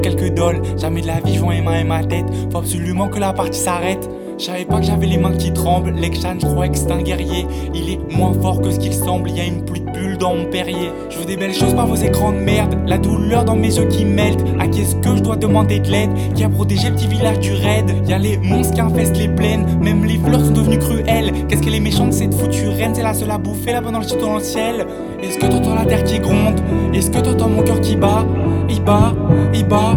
Жанр: Иностранный рэп и хип-хоп / Нью-эйдж / Рэп и хип-хоп